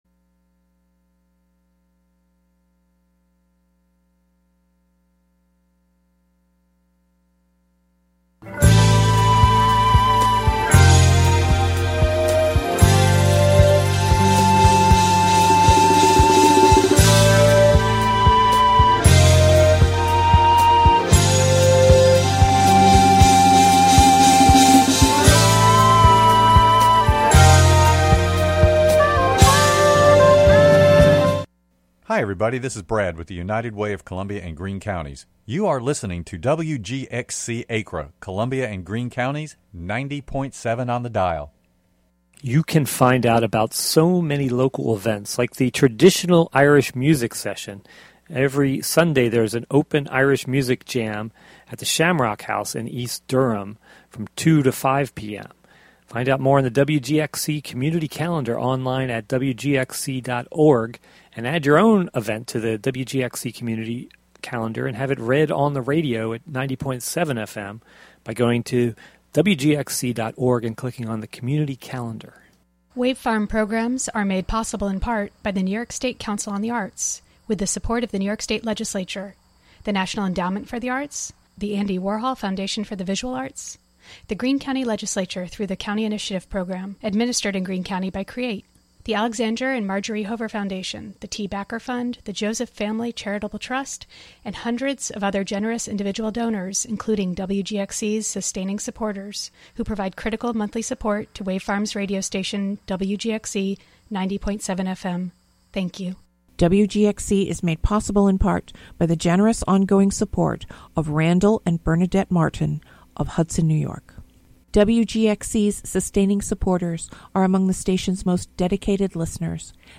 broadcast live from WGXC's Hudson studio.